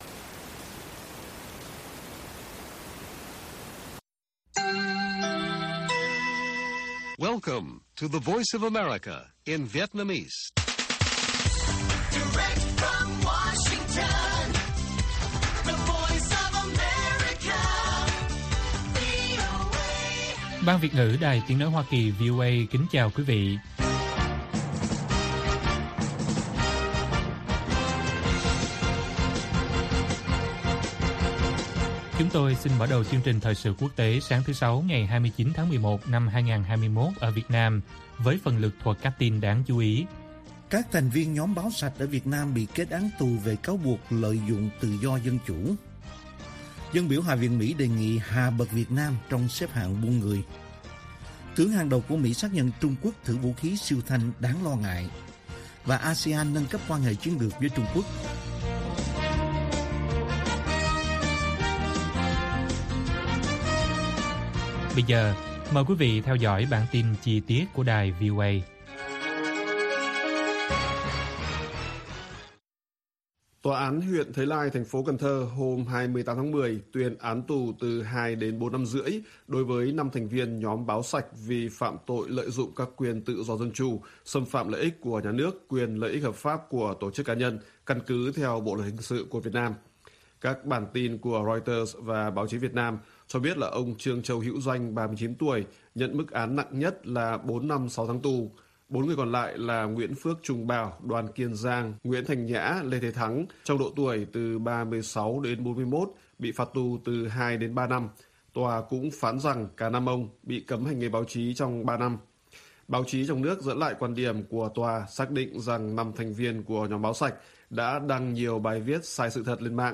Bản tin VOA ngày 29/10/2021